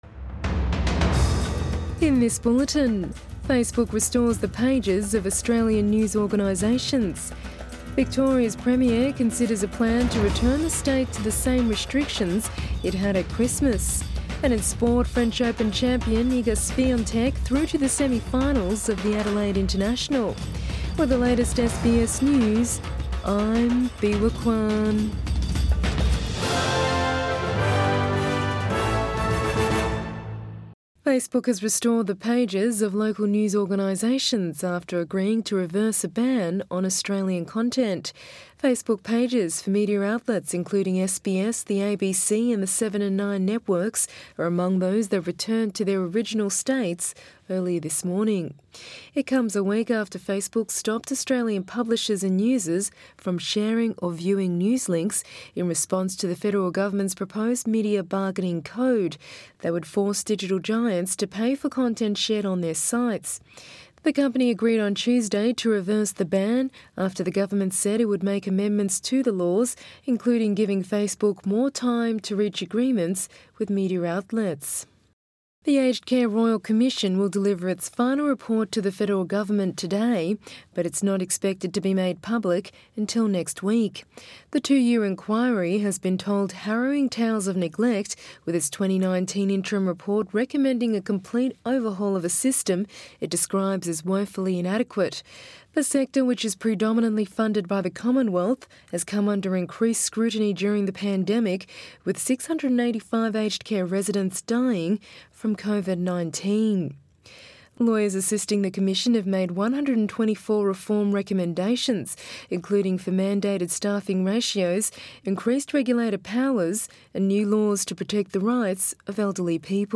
AM bulletin 26 February 2021